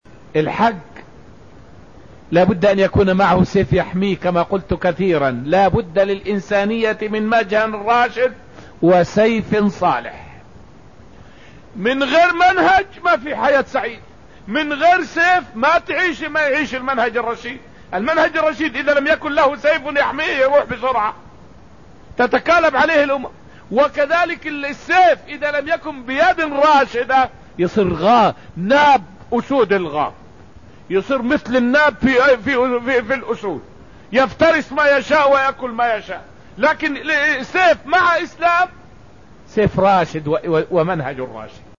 فائدة من الدرس الأول من دروس تفسير سورة الحشر والتي ألقيت في المسجد النبوي الشريف حول سبب تسمية سورة الحشر بسورة بني النضير.